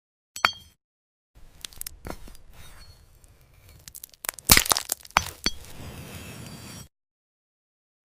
A marbled glass passion fruit sound effects free download
A marbled glass passion fruit is sliced open to reveal glowing golden seeds. Layered cuts, textured sounds, and no distractions — only raw ASMR.